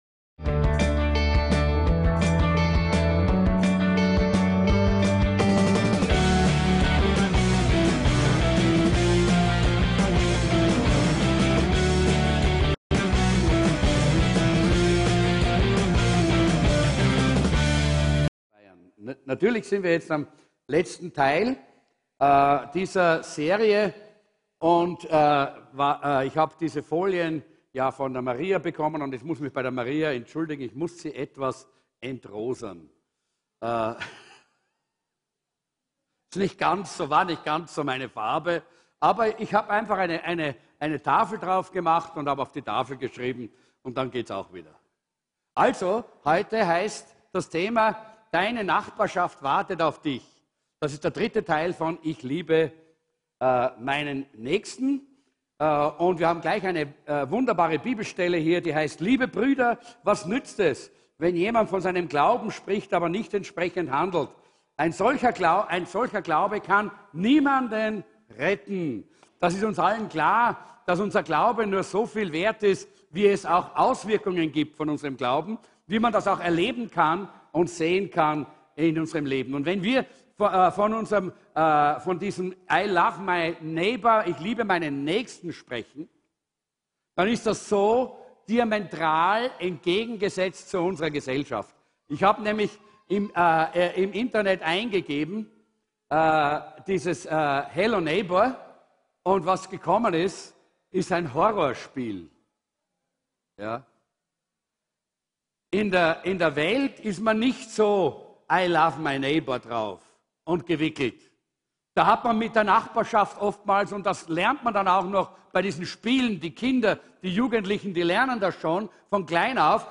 DEINE NACHBARSCHAFT WARTET AUF DICH -TEIL 3"ICH LIEBE MEINEN NÄCHSTEN" ~ VCC JesusZentrum Gottesdienste (audio) Podcast